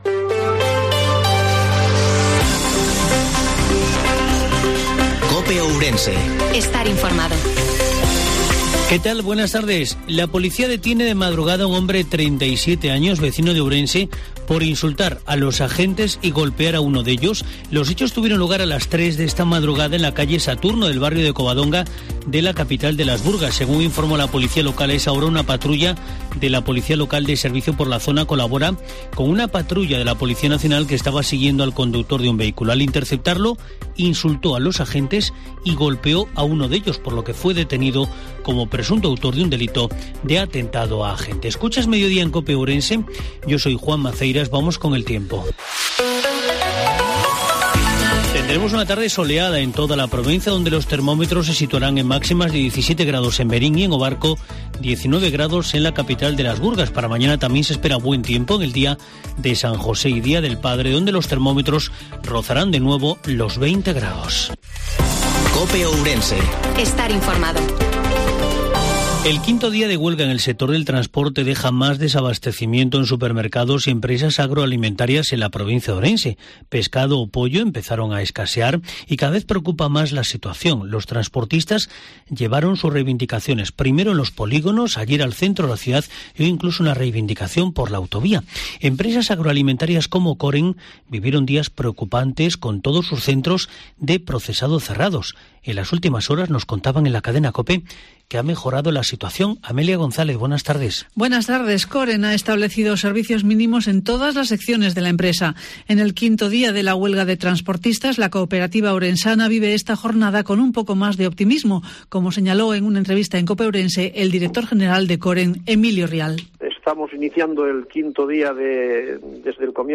INFORMATIVO MEDIODIA COPE OURENSE 18/03/2022